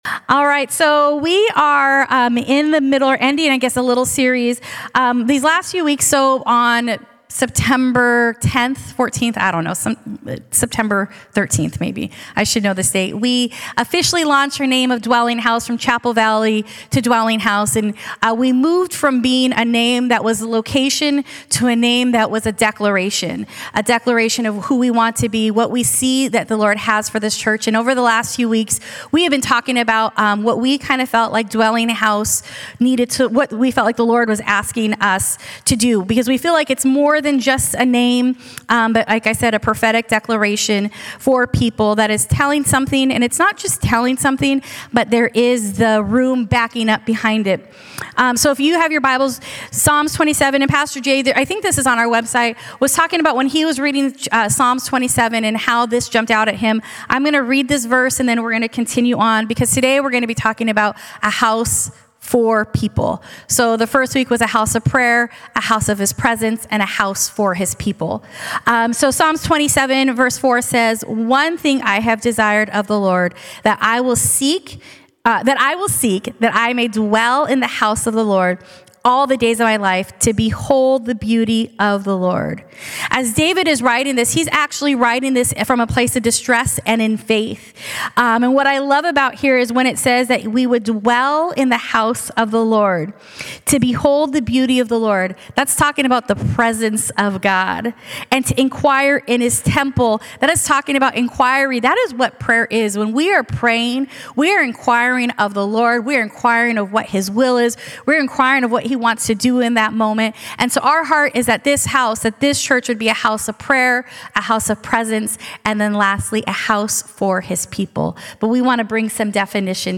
This sermon answers: 1.